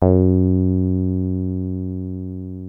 303 F#2 5.wav